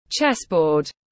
Bàn cờ vua tiếng anh gọi là chessboard, phiên âm tiếng anh đọc là /ˈtʃes.bɔːd/
Chessboard /ˈtʃes.bɔːd/